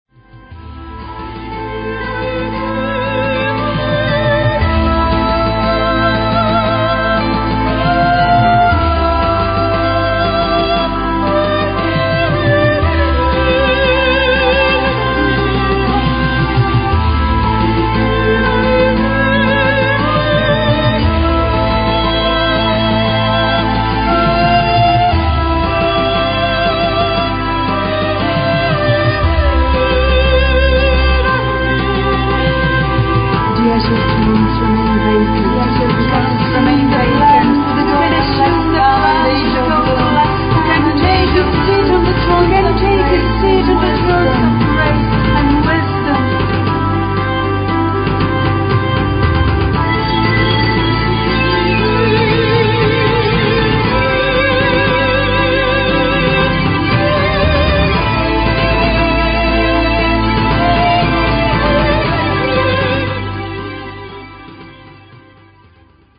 vocals, drum machine
guitars, drums machine
keyboards, harmonizing